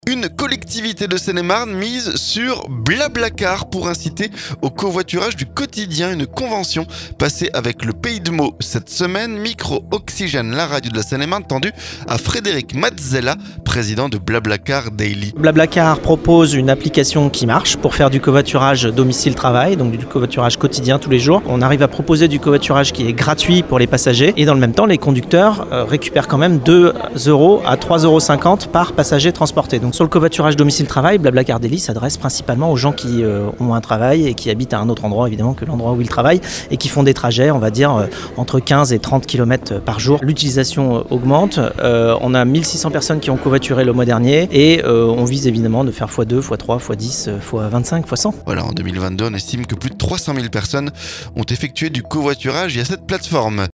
Une collectivité de Seine-et-Marne mise sur BlaBlacar pour inciter au covoiturage du quotidien. Une convention passée avec le Pays de Meaux cette semaine. Micro Oxygène la radio de la Seine-et-Marne tendu à Frédéric Mazzella, président de BlaBlacar Daily.